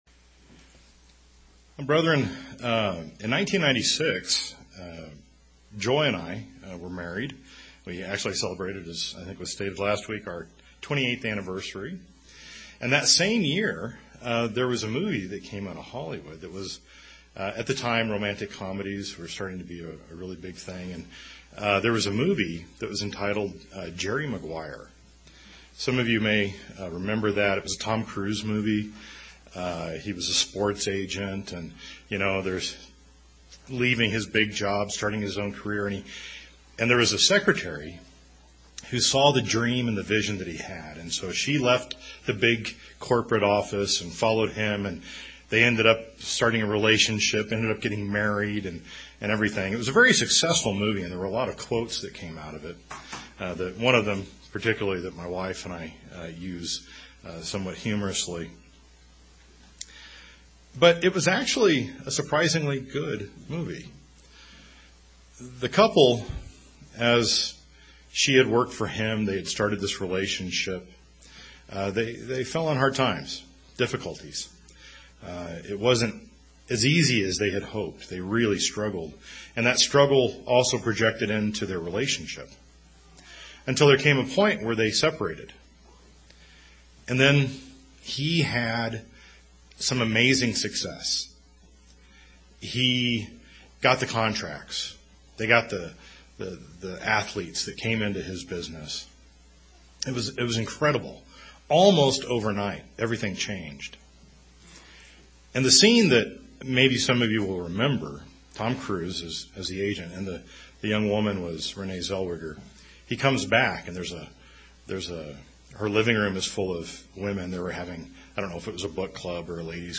This sermon is the eighth in the series of "Which Church Am I?".